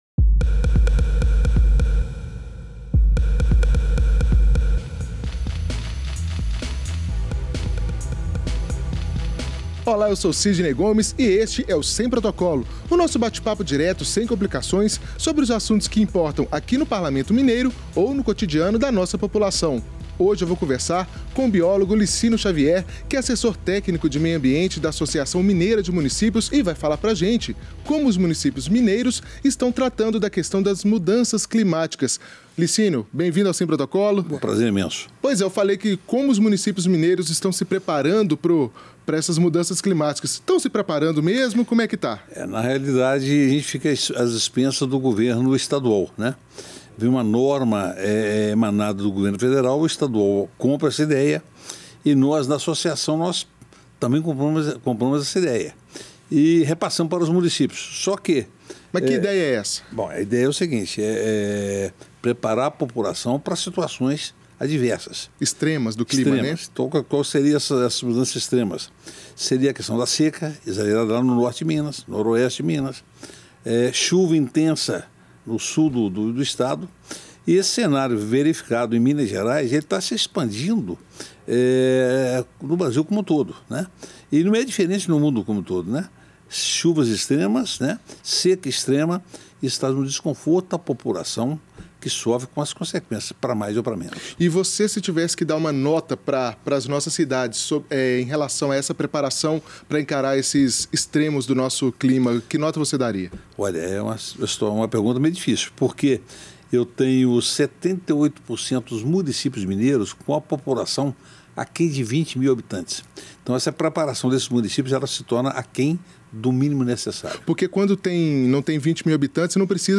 Nesta conversa, ele fala sobre o que falta para que as prefeituras encarem o problema das mudanças climáticas com a urgência necessária.